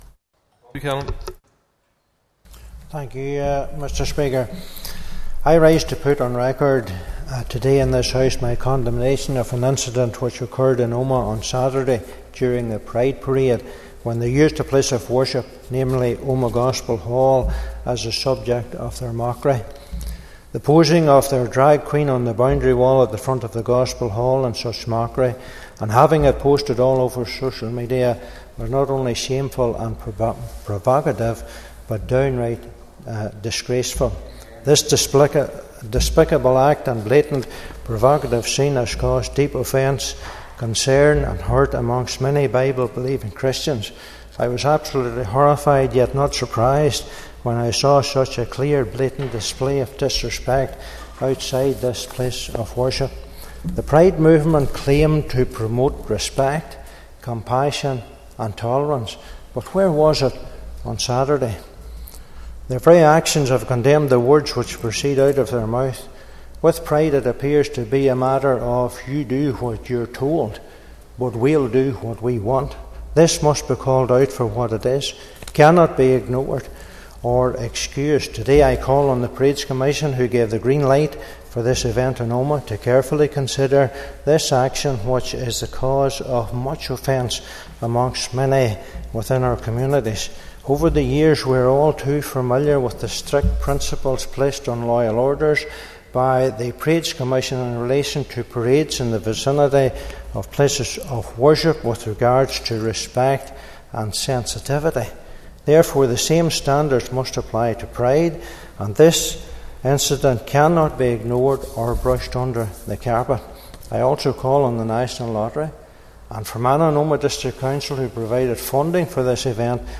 Mr Buchanan raised the issue in the Northern Ireland Assembly and called on the Parades Commission to consider the action and for a public apology from the organisers.